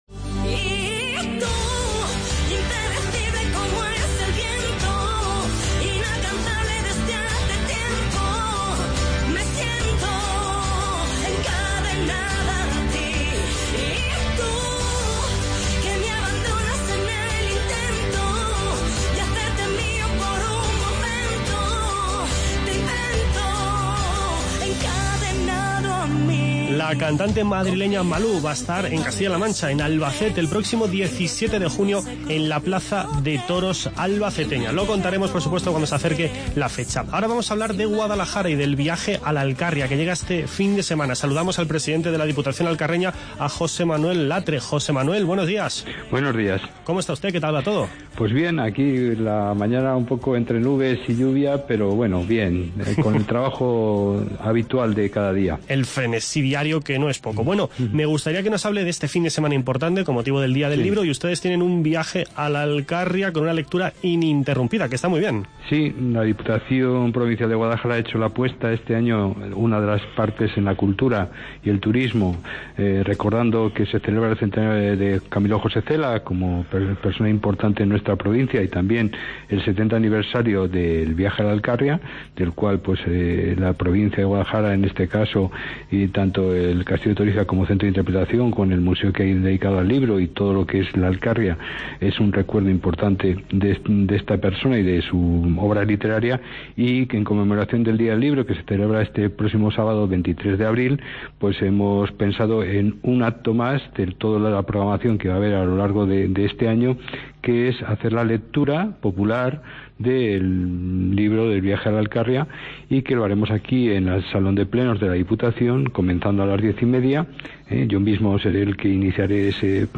Hablamos, en primer lugar del libro "Viaje a la Alcarria" de Camilo José Cela con el presidente de la Diputación Provincial de Guadalajara. José Manuel Latre nos explica que este sábado tendrá lugar en Guadalajara una lectura pública e ininterrumpida coincidiendo con el "Día del Libro". A continuación nos marchamos hasta el pleno de las Cortes para conversar con Francisco Nuñez.